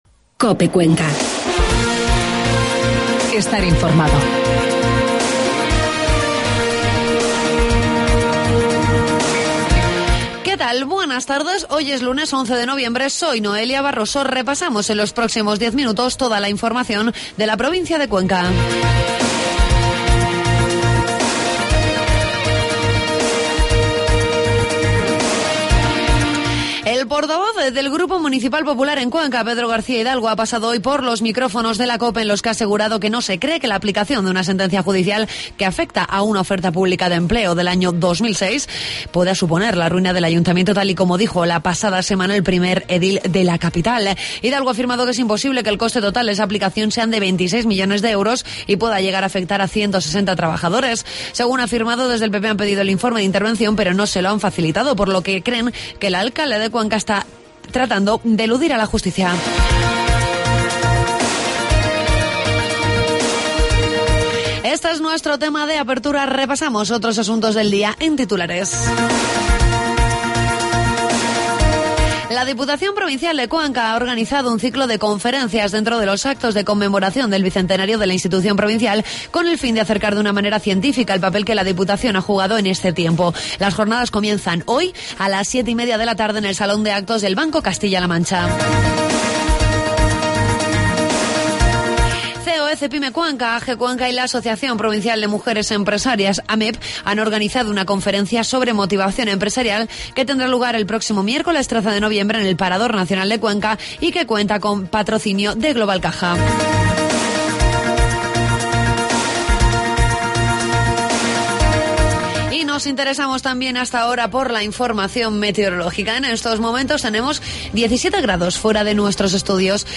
informativos de mediodía